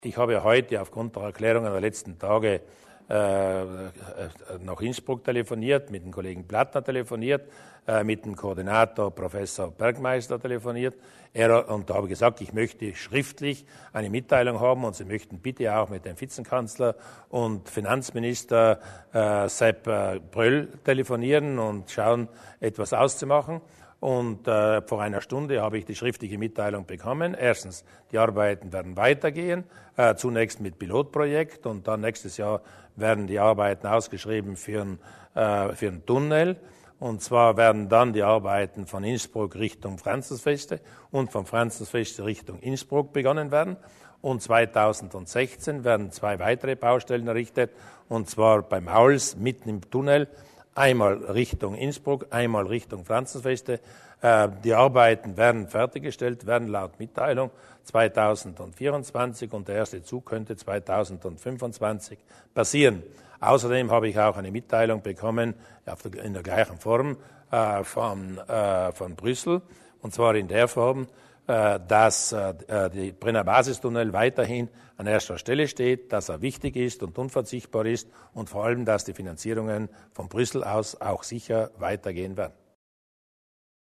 Landeshauptmann Durnwalder zu den Zusicherungen für den Brennerbasis-Tunnel